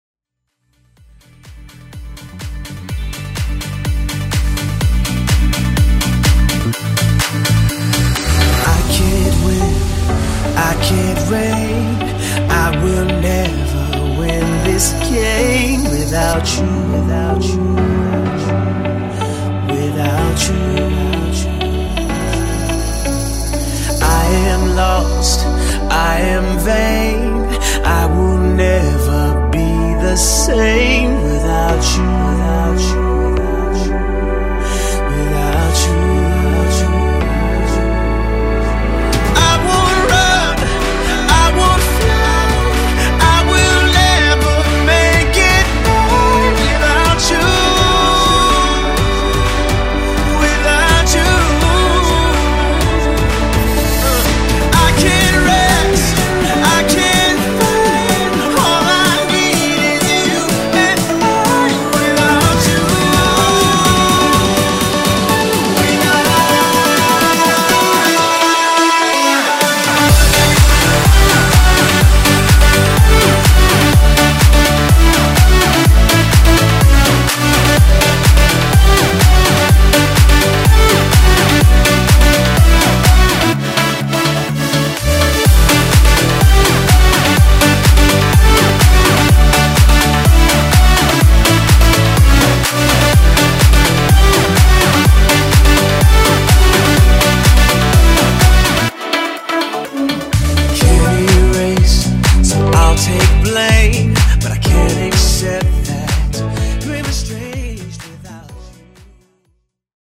BPM: 125 Time